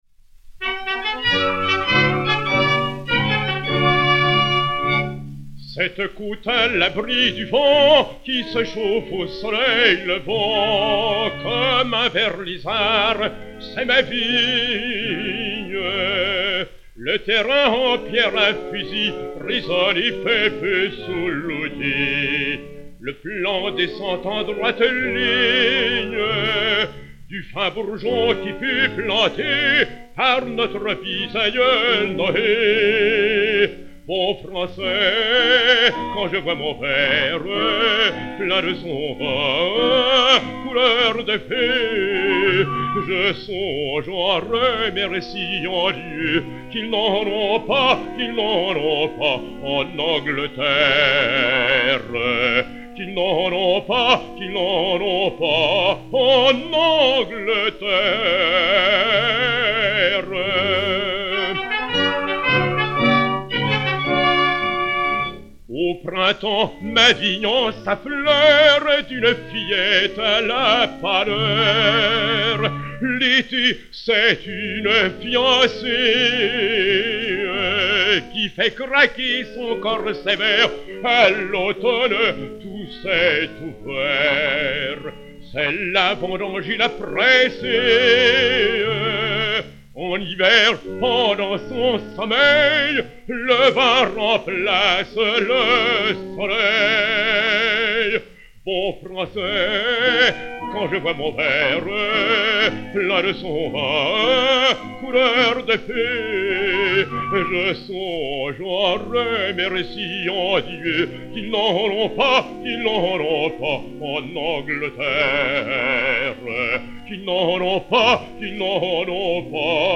baryton français
marche française (par.